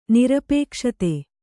♪ nirapēkṣate